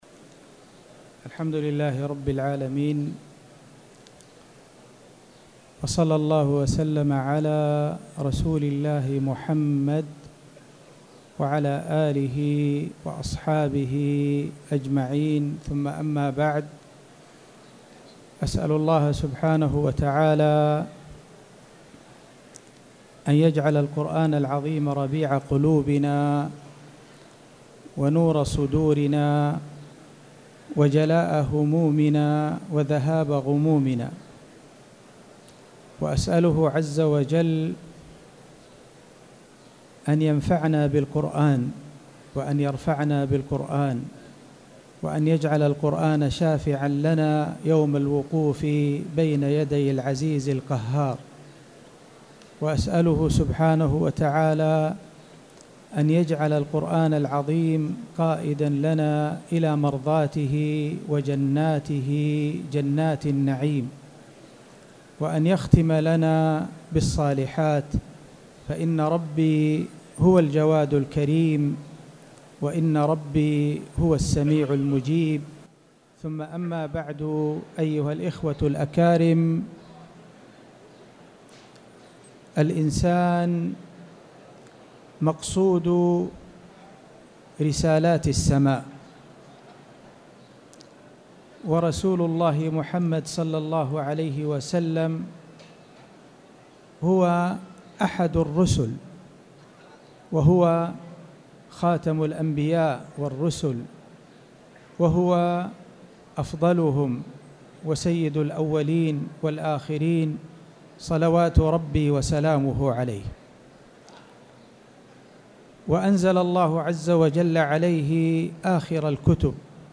تاريخ النشر ١٦ جمادى الأولى ١٤٣٨ هـ المكان: المسجد الحرام الشيخ